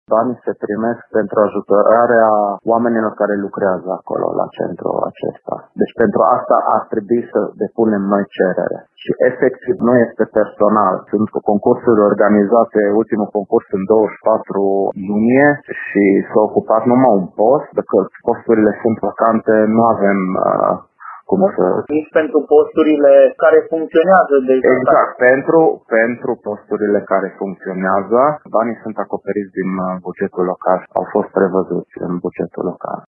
Sumele erau destinate personalului din centru, dar deocamdată căminul nu are suficienţi angajaţi, spune viceprimarul Farkas Imre.